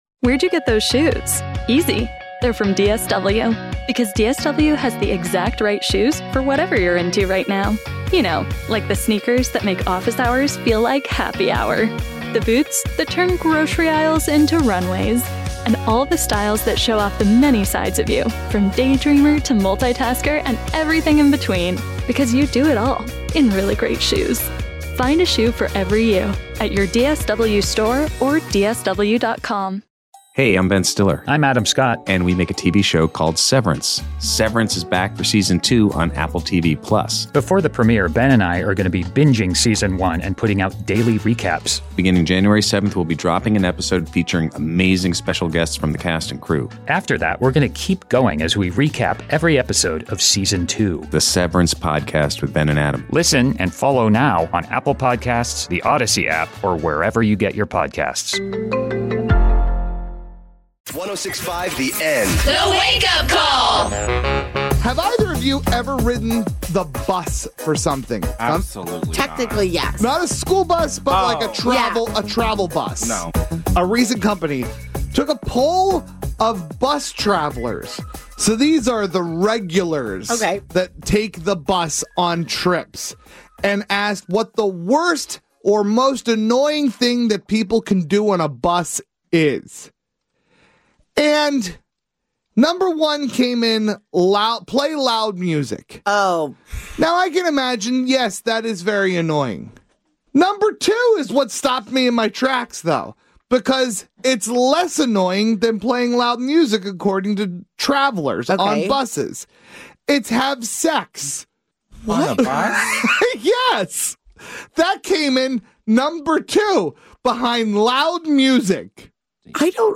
Comedy Entercom Communications Corp Wake Did You Know
The Wake Up Call is a morning radio show based in Sacramento, California, and heard weekday mornings on 106.5 the End.